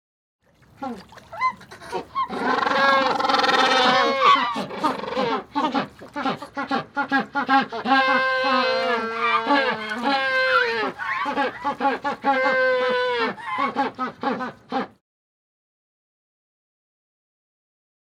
animal
Magellanic Penguins Small Group Braying 2